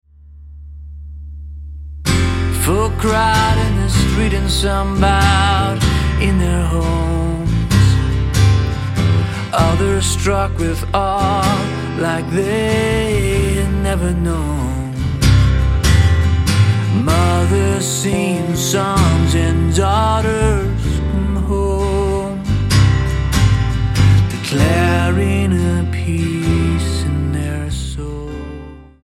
STYLE: Roots/Acoustic
some fine electric guitar